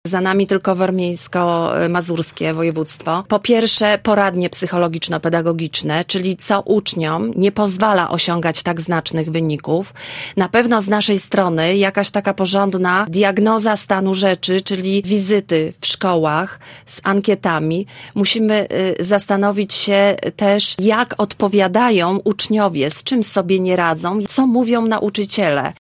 Wynik Lubuszan skomentowała w „Rozmowie Punkt 9” lubuska wicekurator oświaty – Katarzyna Pernal-Wyderkiewicz: